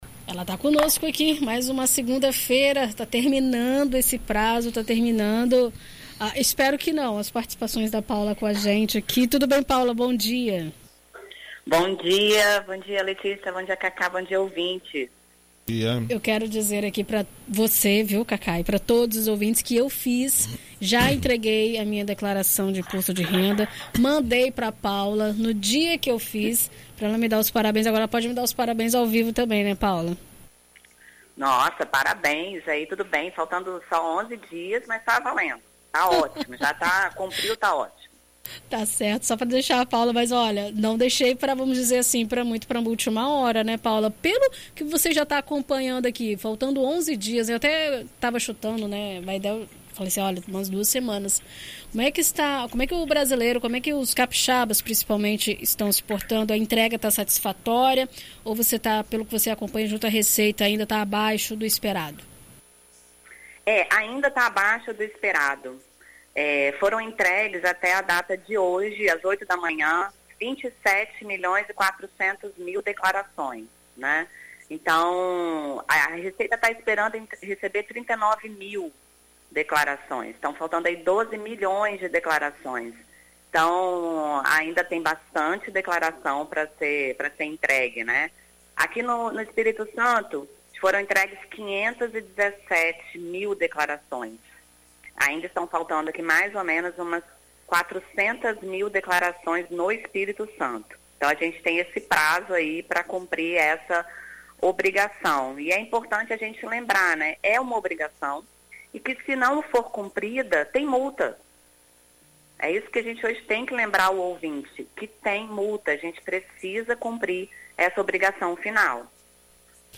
Na coluna especial sobre Imposto de Renda 2023, na BandNews FM Espírito Santo nesta segunda-feira (22)